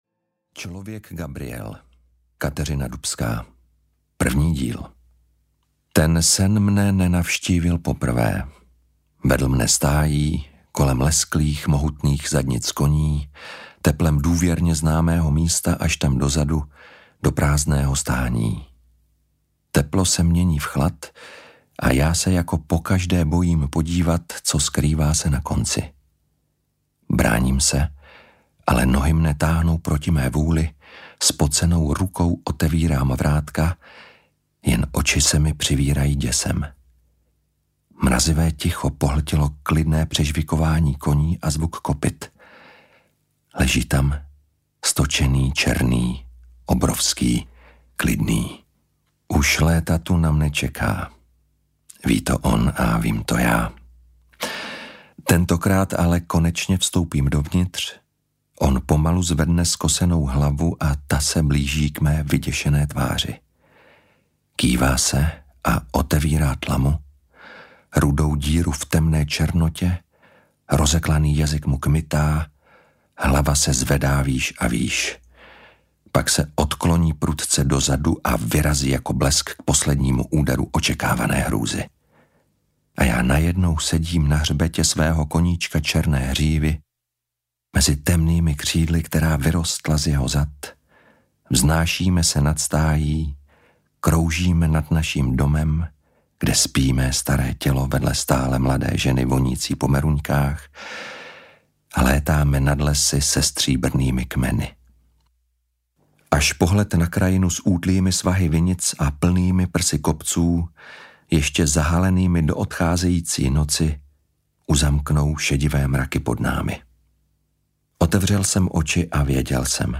Člověk Gabriel audiokniha
Ukázka z knihy
clovek-gabriel-audiokniha